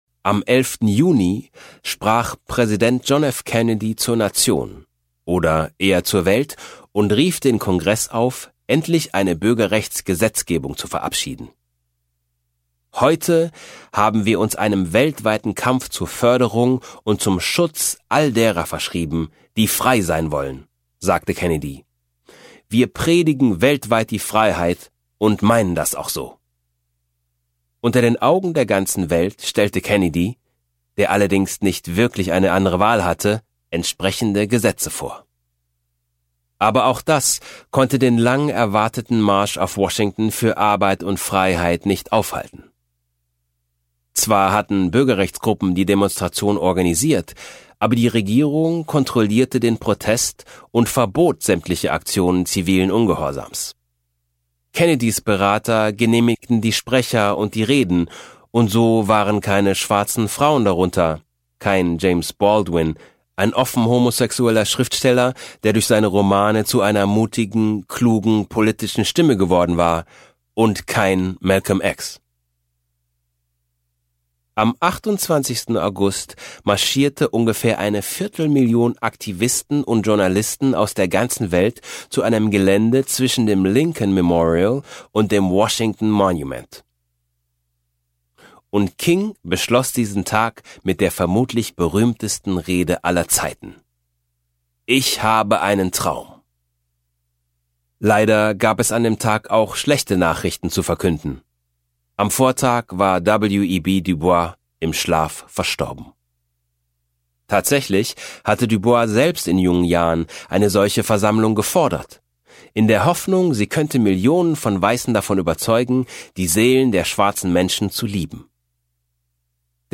Stamped – Rassismus und Antirassismus in Amerika Ungekürzte Lesung mit Tyron Ricketts
Tyron Ricketts (Sprecher)